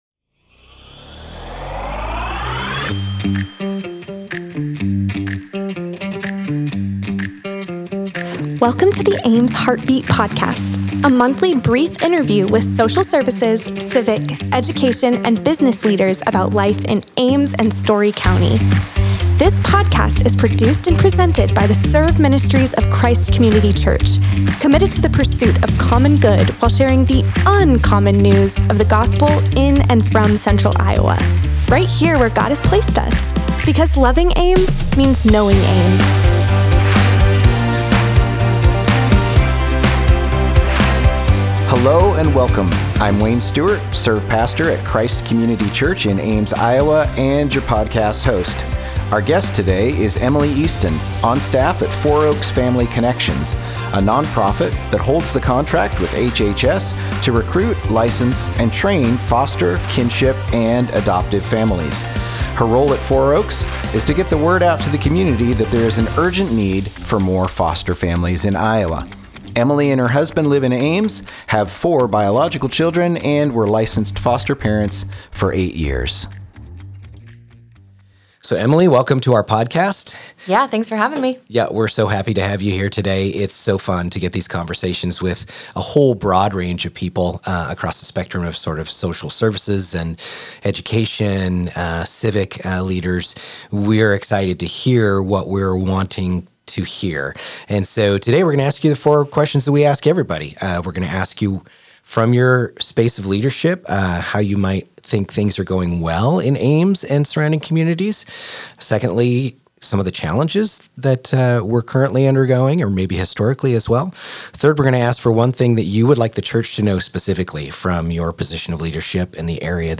The Ames Heartbeat Podcast features brief interviews with civic, education, social services, and business leaders in Ames and surrounding communities.